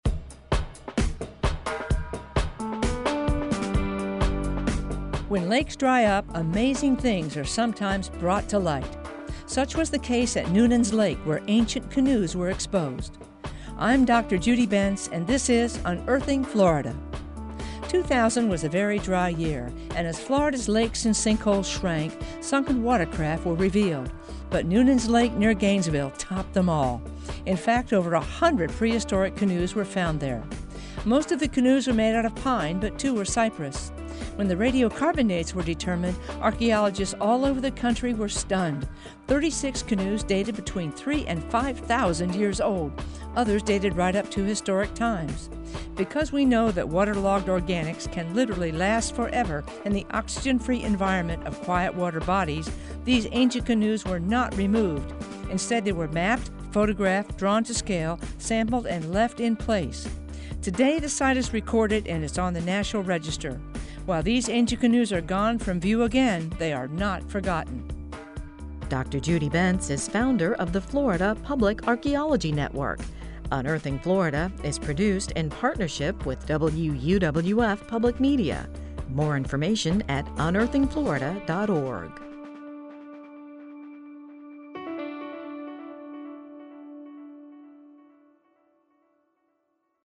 Written, narrated, and produced by the University of West Florida, the Florida Public Archaeology Network and WUWF Public Media.